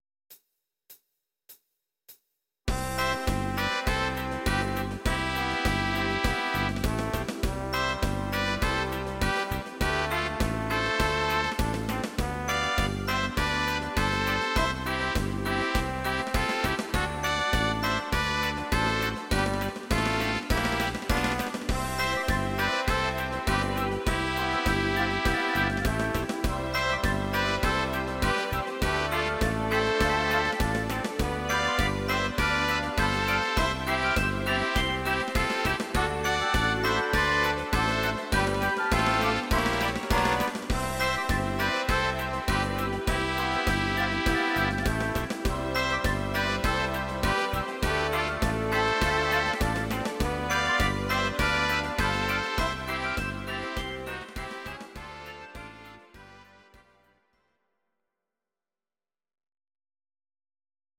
These are MP3 versions of our MIDI file catalogue.
Please note: no vocals and no karaoke included.
instr. Orchester